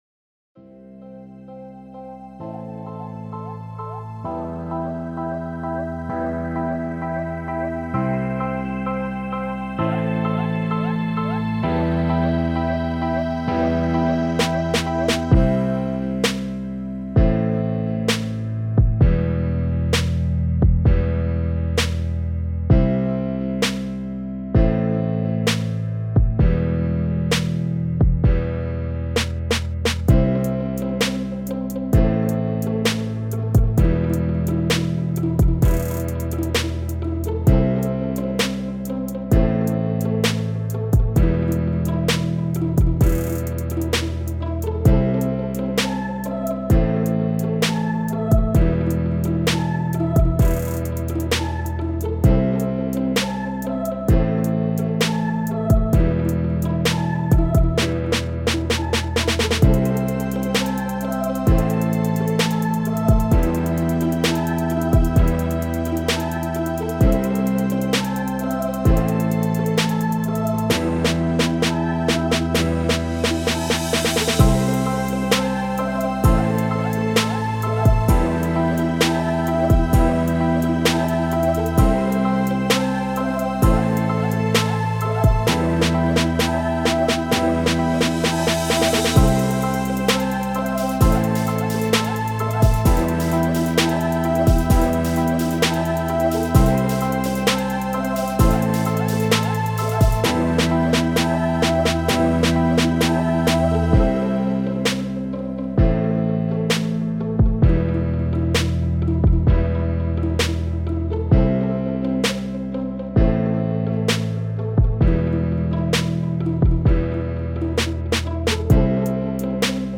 Hallo zusammen, meine Produktionen (Rap) klingen mittlerweile schon ziemlich professionell und ich bin zufrieden soweit, allerdings nicht mit den...
aber lade jetzt mein ein instrumental von mir hoch.